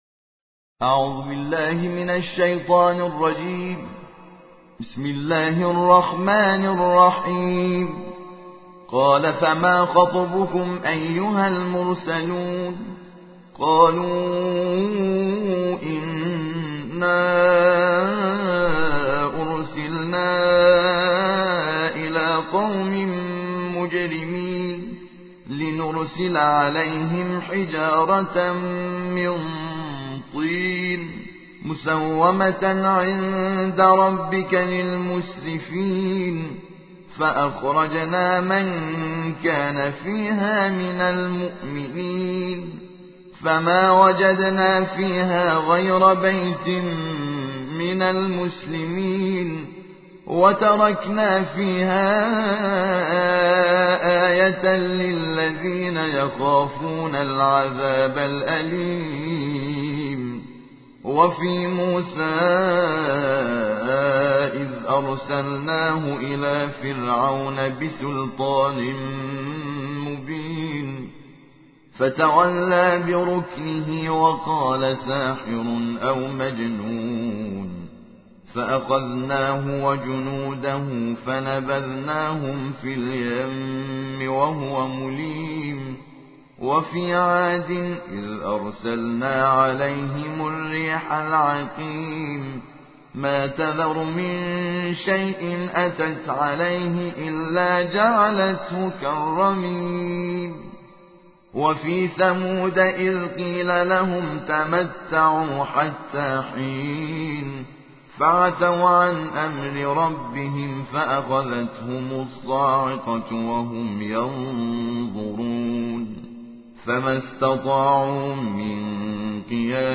ترتیل جزء سی ام - حامد شاکر نژاد